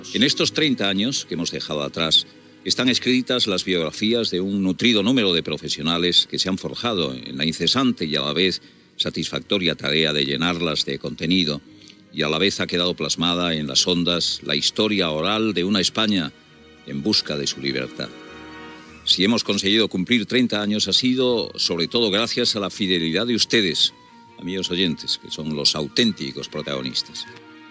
Comentari de Luis del Olmo, en el 30è aniversari del programa "Protagonistas"
Info-entreteniment
FM